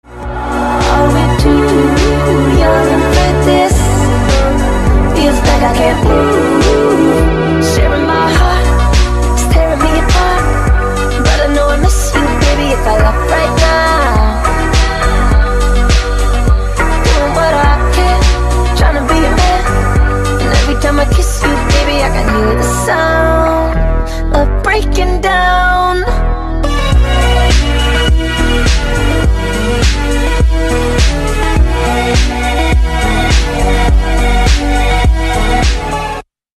Рингтоны Альтернатива
Рингтоны Электроника